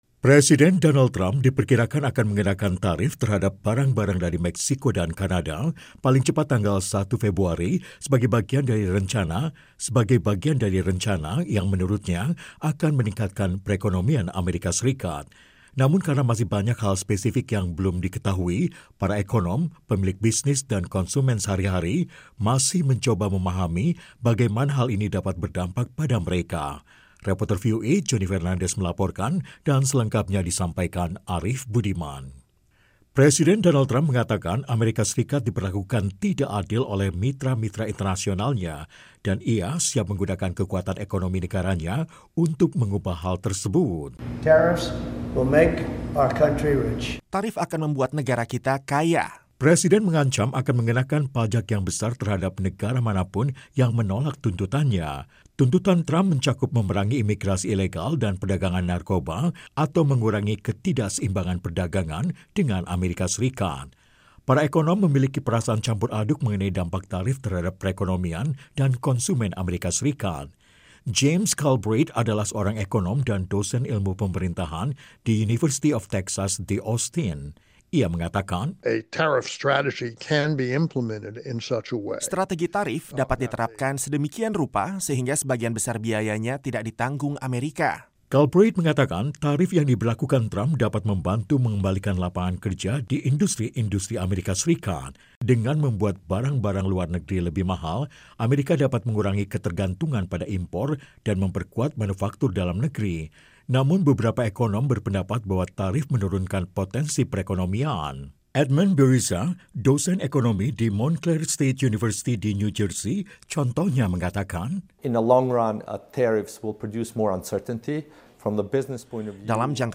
Presiden Trump diperkirakan akan mengenakan tarif terhadap barang-barang dari Meksiko dan Kanada paling cepat tanggal 1 Februari sebagai bagian dari rencana yang menurutnya akan meningkatkan perekonomian AS. Para ekonom, pemilik bisnis, dan masyarakat menduga-duga dampaknya. Tim VOA melaporkan.